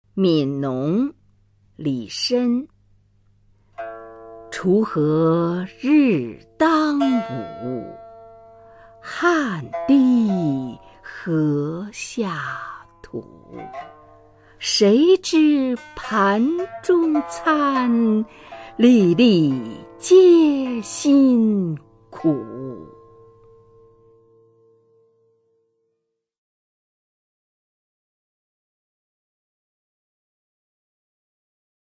[隋唐诗词诵读]李绅-悯农_锄禾 配乐诗朗诵